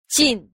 a. 盡 – jìn – tẫn
jin.mp3